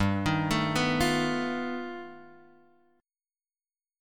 G Augmented 9th